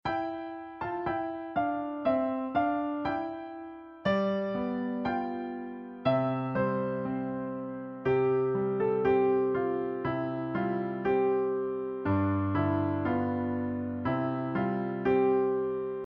Children's Nursery Rhyme Song Lyrics and Sound Clip